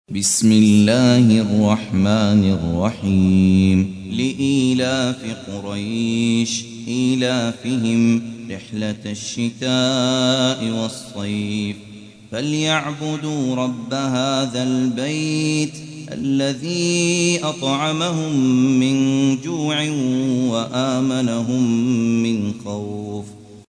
106. سورة قريش / القارئ